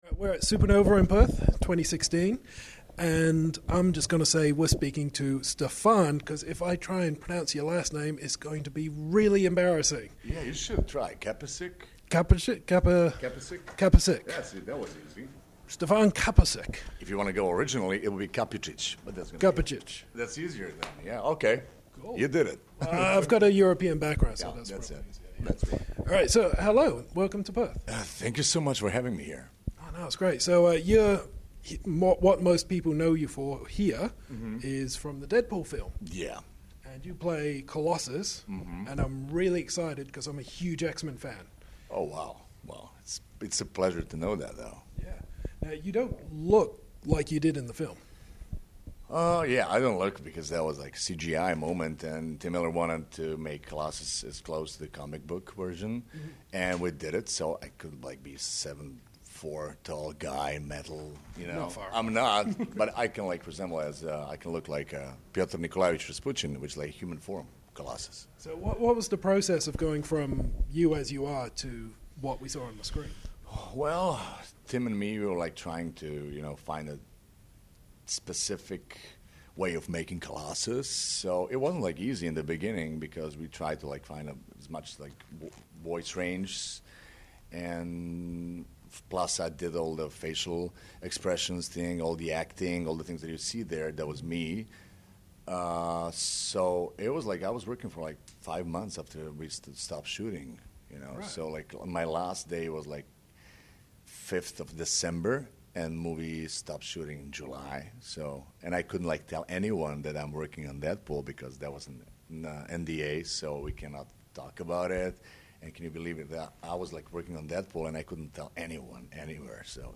Not ones to split up a team we’re back with Stefan Kapičić, who played Colossus in epic hit Deadpool. We recommend listening to this one, because it’s like I’m talking to Colossus!